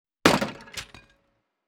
guard-crash.wav